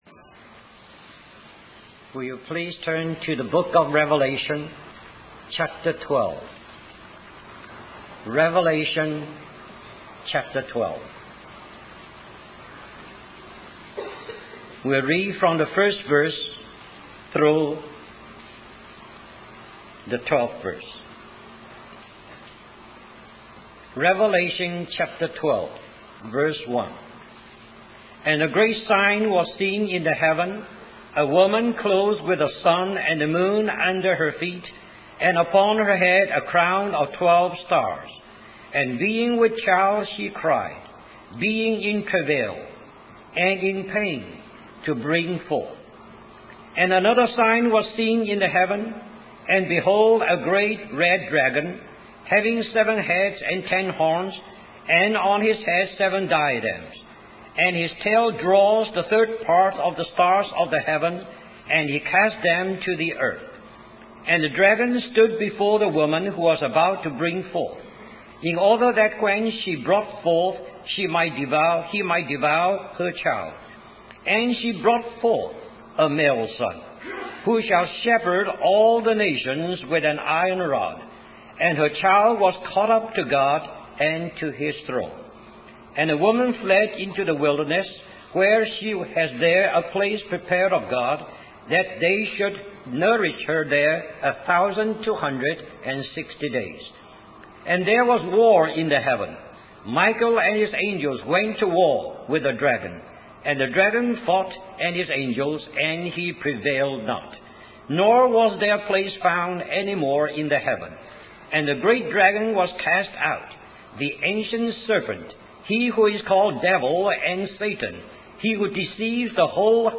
In this sermon, the speaker emphasizes the immense pressure that believers face in the world today. They highlight how the enemy is constantly tempting, deceiving, and attacking believers from all sides.